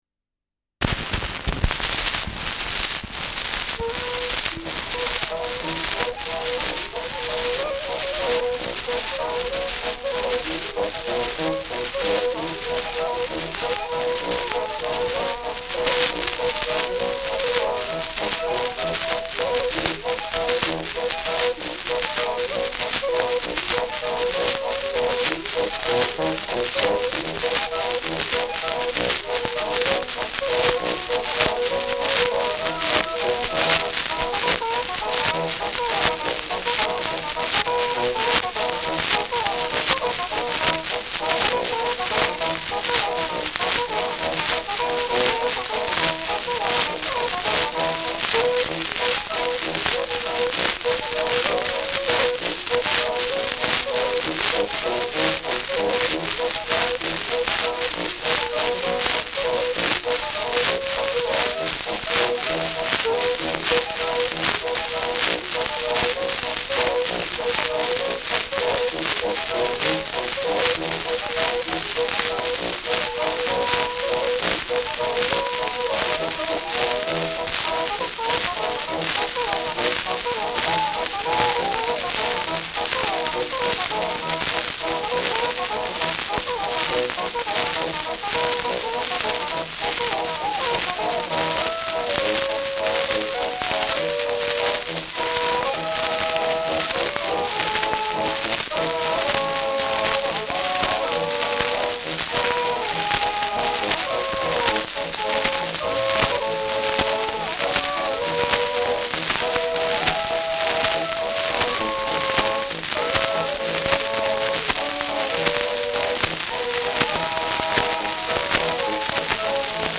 Schellackplatte
Starkes Grundrauschen : Starkes Nadelgeräusch
[Nürnberg?] (Aufnahmeort)